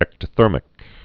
(ĕktə-thûrmĭk) also ec·to·ther·mal (ĕktə-thûrməl)